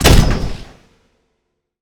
sci-fi_weapon_rifle_med_shot_01.wav